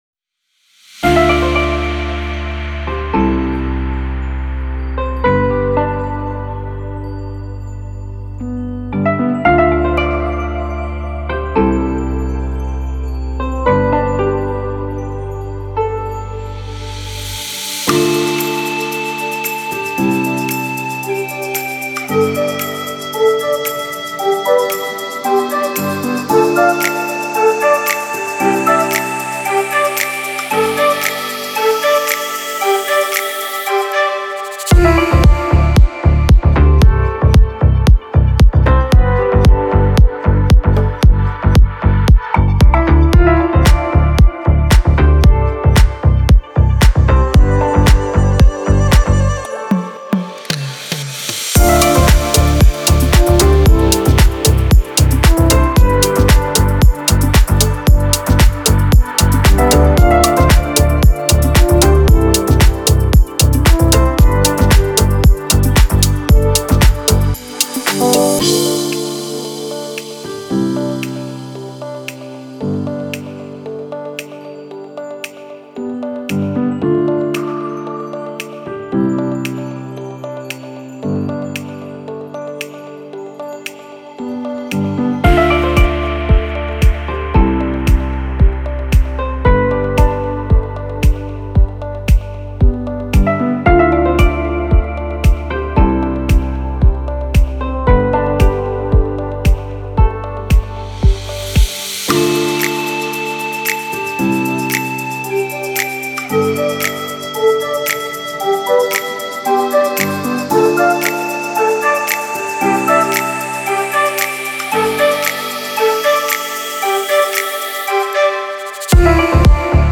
атмосферная электронная композиция